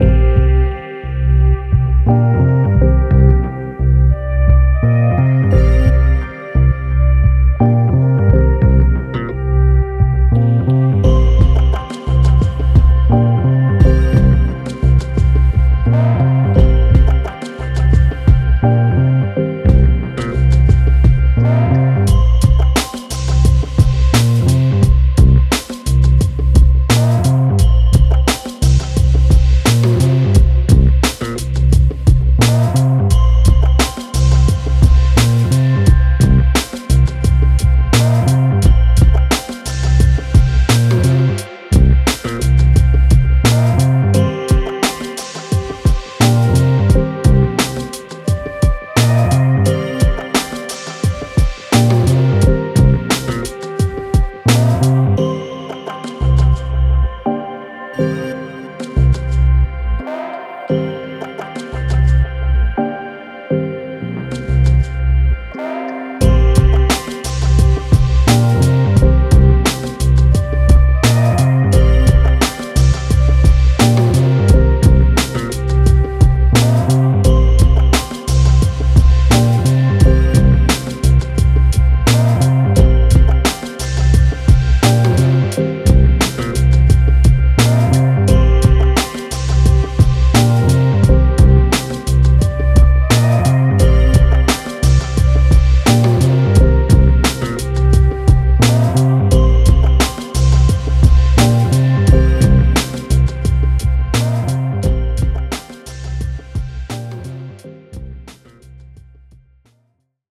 エレクトリックキー＆ピアノ: スムーズでソウルフルなコードとメロディックな進行で、ハーモニックな基盤を構築できます。
フルート＆ストリングス: 表現力豊かな要素でシネマティックな感情と奥行きを追加できます。
オーガニック要素と電子要素を組み合わせ、独自のサウンドパレットを実現しています。
デモサウンドはコチラ↓
Genre(s):  Downtempo / Triphop
Tempo Range: 87bpm
Key: Cm